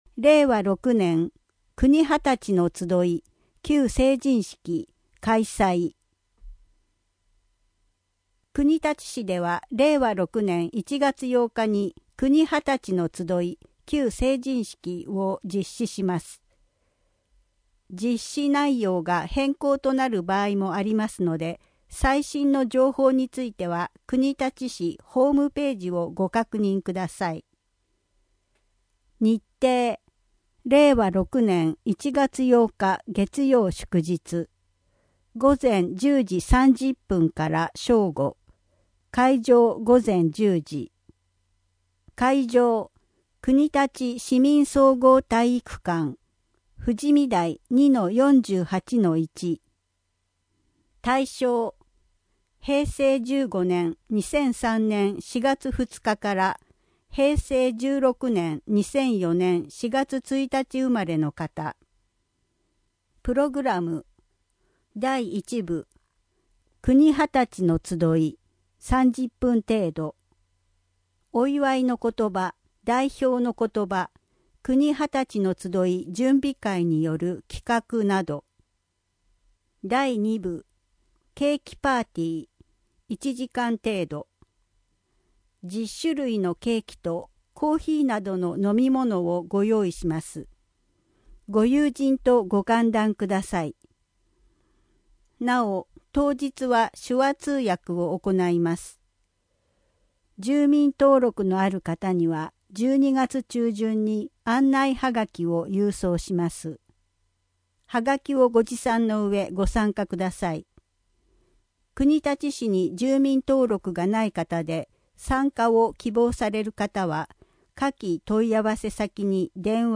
第173号[12月5日発行]音訳版2